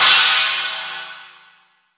43_14_cymbal.wav